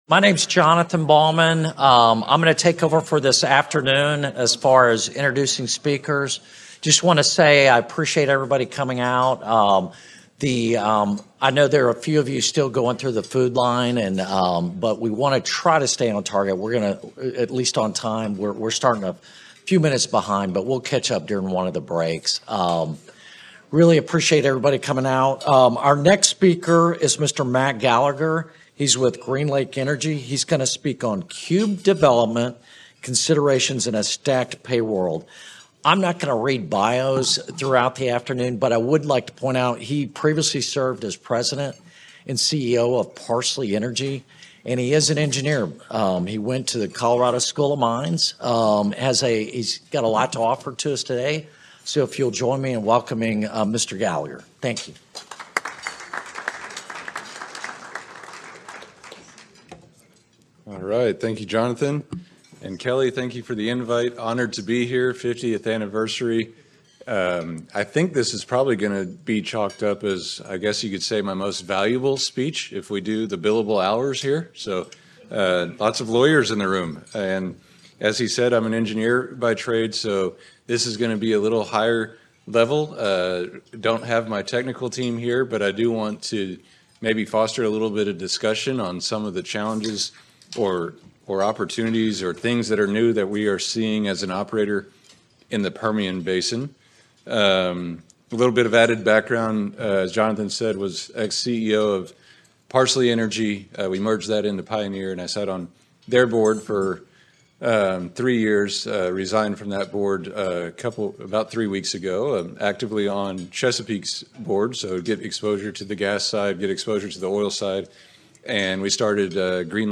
Keynote presentation
Originally presented: Apr 2024 Oil, Gas and Mineral Law Institute